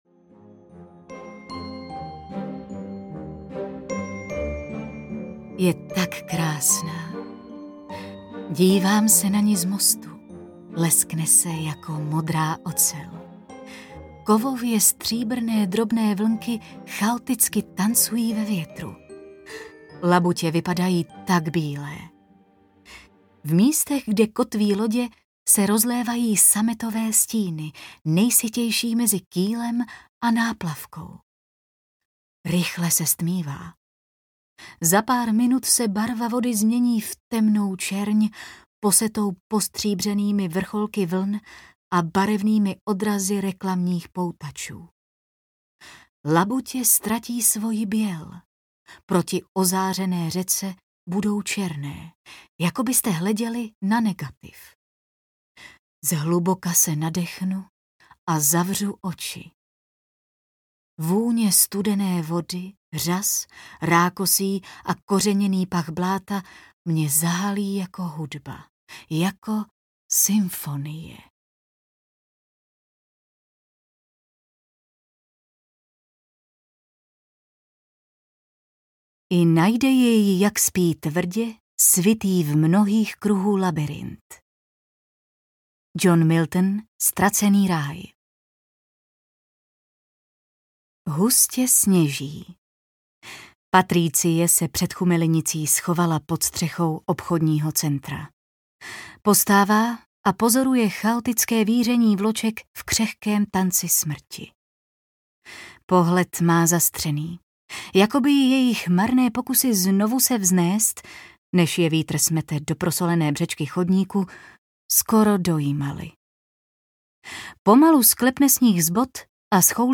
Achernar audiokniha
Ukázka z knihy